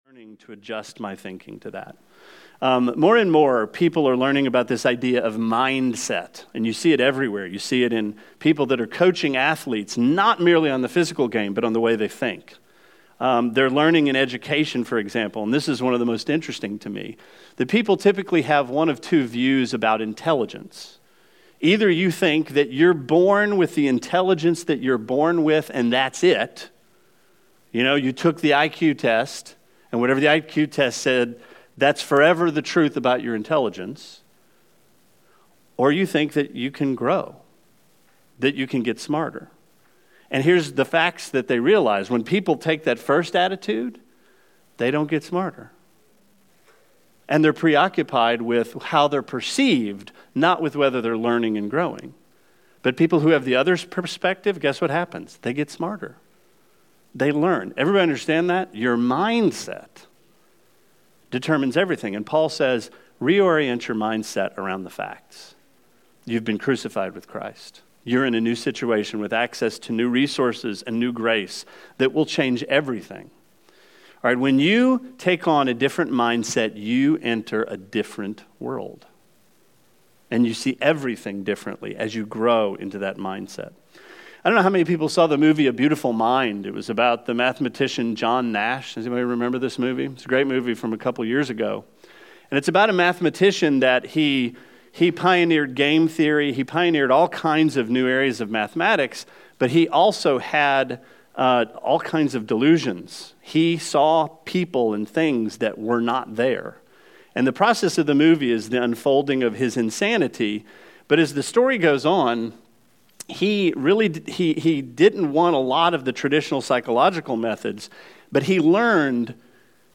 Sermon 02/02: Grace is God's Help
I apologize that some of the sermon was not recorded.